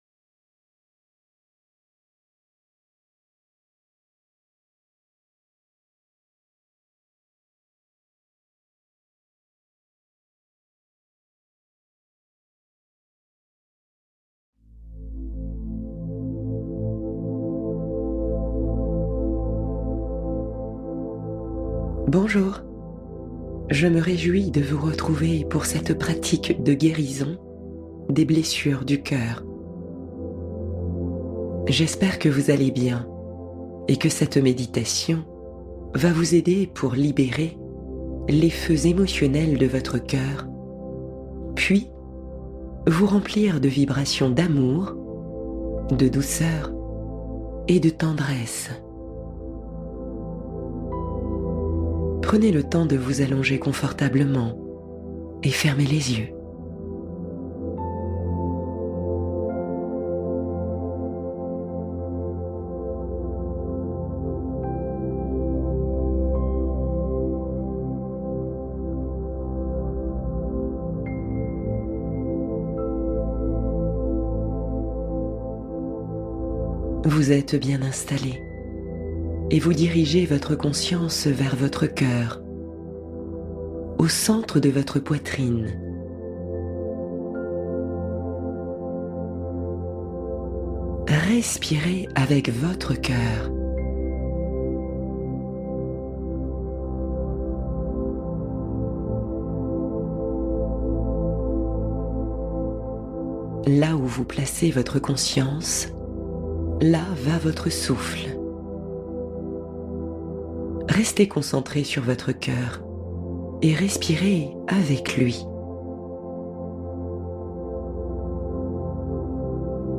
Cœur Apaisé : Méditation guidée pour cultiver la paix intérieure